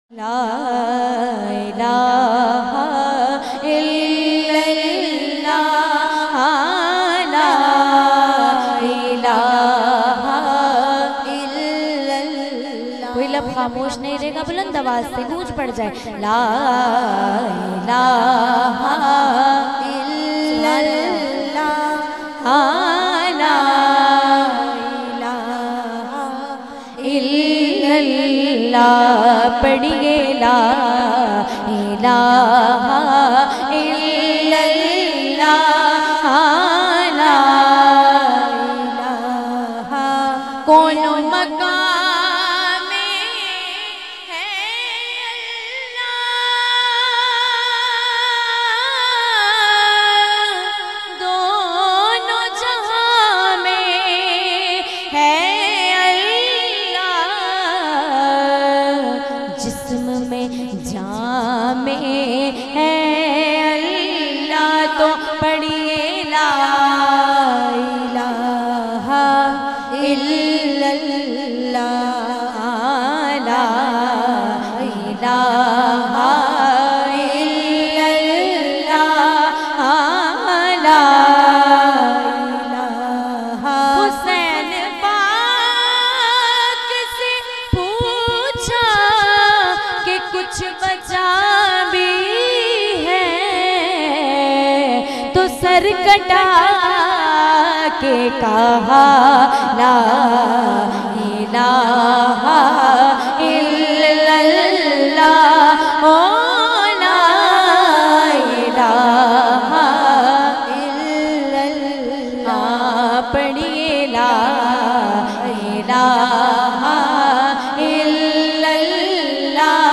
Download and Listen to kalma sharif download mp3 & listen online in a Heart-Touching Voice, Along with the Lyrics.